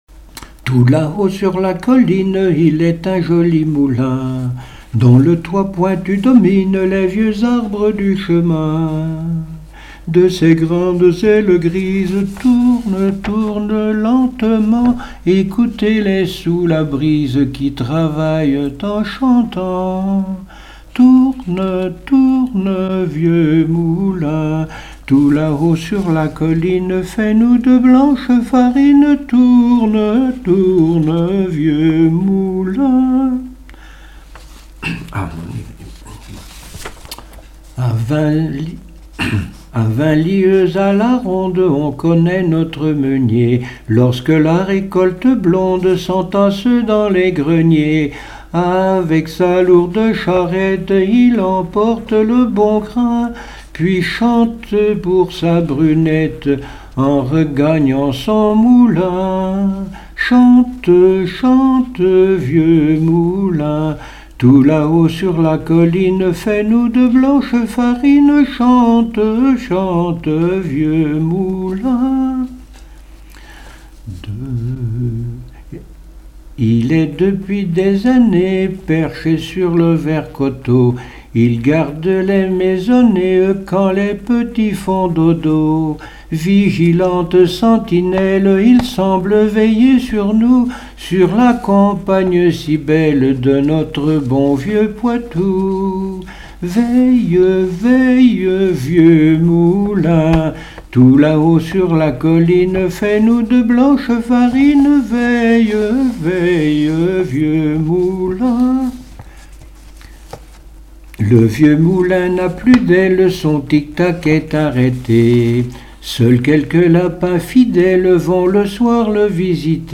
Genre strophique
Chansons et témoignages
Pièce musicale inédite